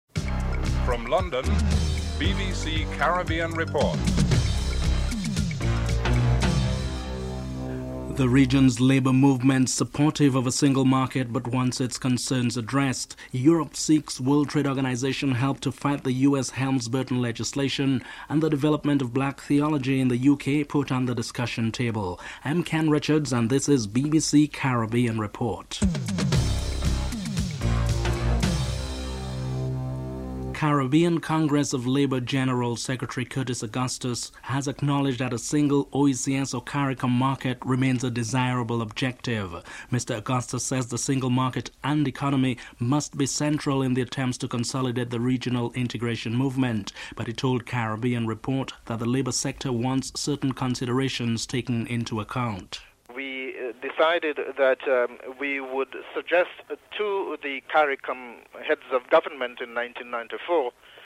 He wants to see the RSS extended to include the entire Caribbean as a response to the growing drug menace. Prime Minister Vaughn Lewis is interviewed (04:22-05:23)
With volcano evacuators dislocated and some residents overseas the authorities in Montserrat are considering postponing the 1996 general elections. Chief Minister Reuben Meade is interviewed (05:24-06:43)